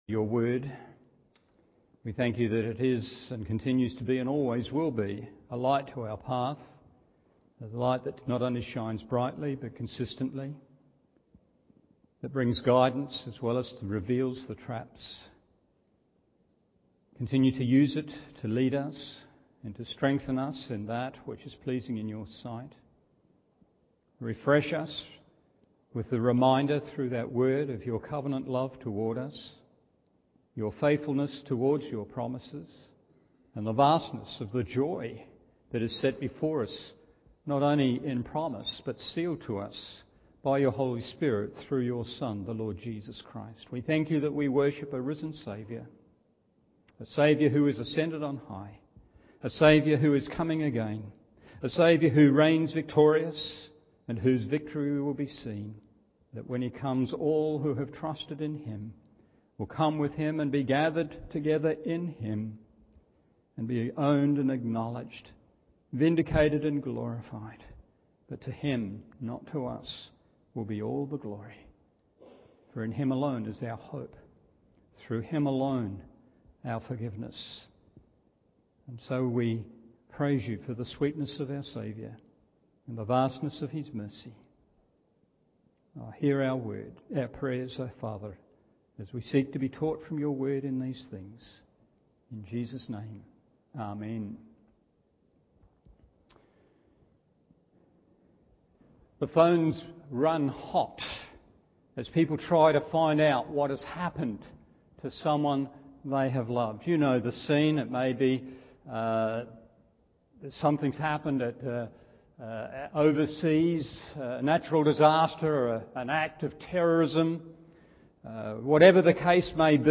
Evening Service…